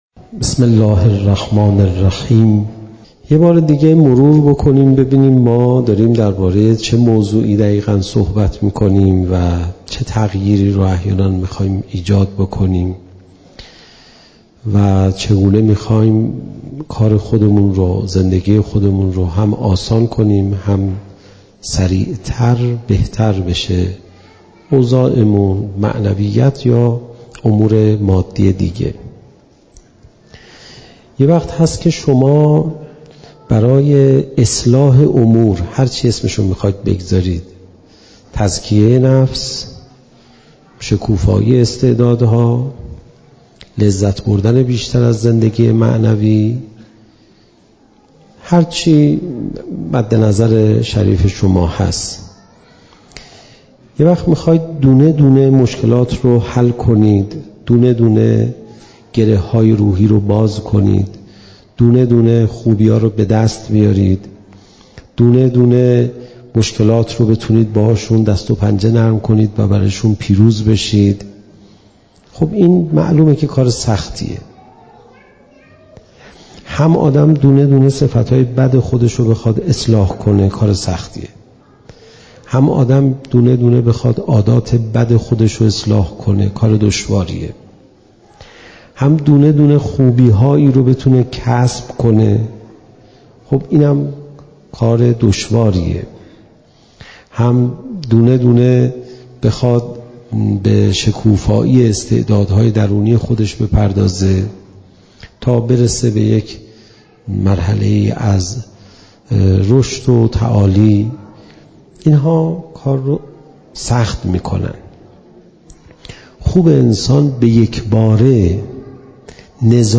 زمان: 49:45 | حجم: 11.6 MB | مکان: آستان مقدس حضرت صالح (علیه السلام) - تهران | تاریخ: رمضان 1401ش